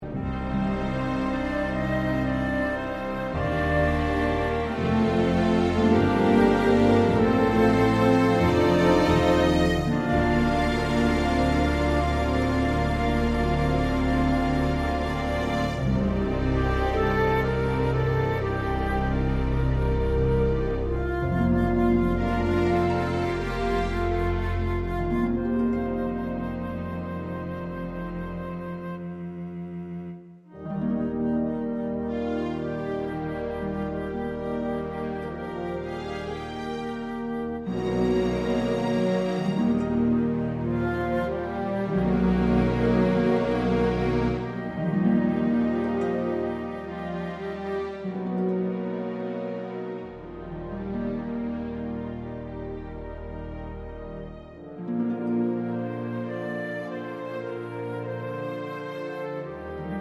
Down 3 Semitones Easy Listening 4:31 Buy £1.50